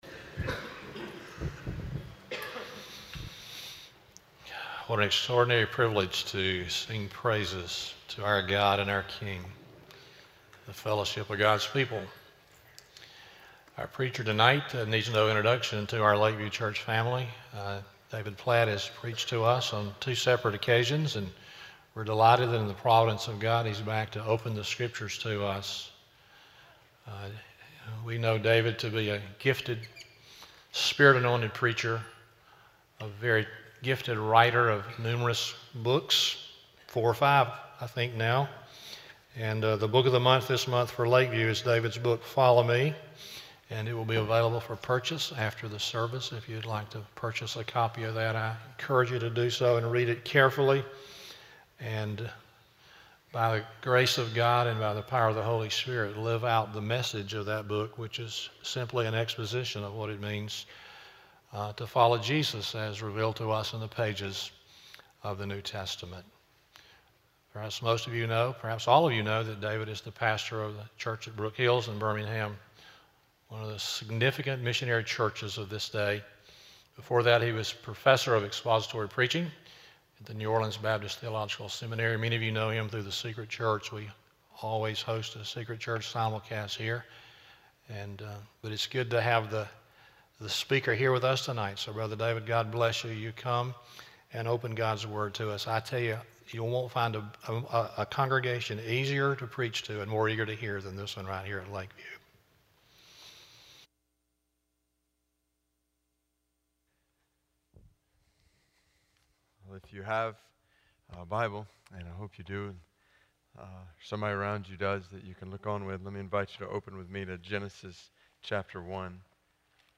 Guest Speaker – David Platt